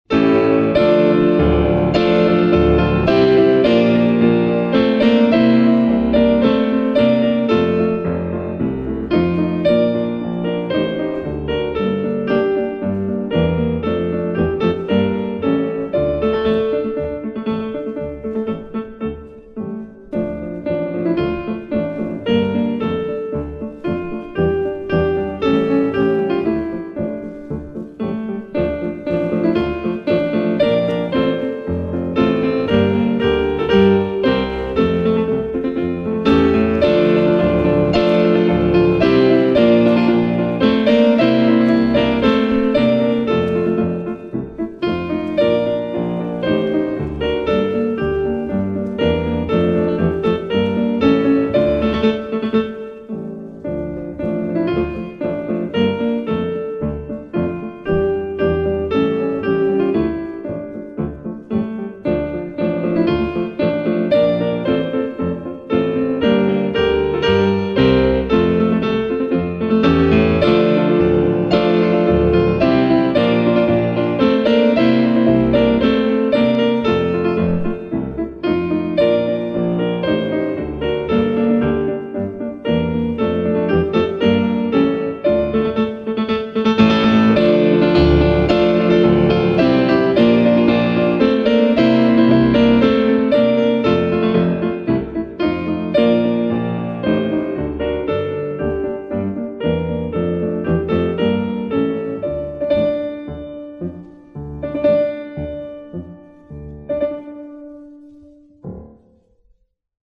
Исполняет: фортепиано Исполнение 1980г.